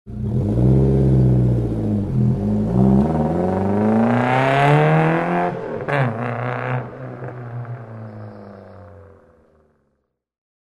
Звуки езды в автомобиле
Гул гоночного мотора, разгон и стремительный уход